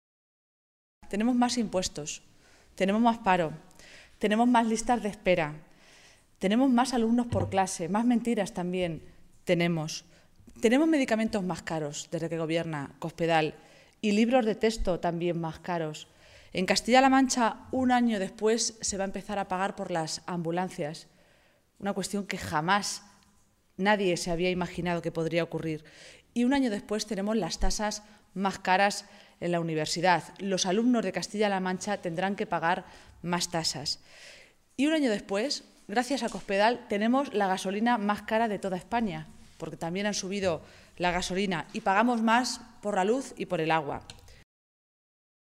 Cristina Maestre, portavoz de la Ejecutiva Regional del PSOE de Castilla-La Mancha
Cortes de audio de la rueda de prensa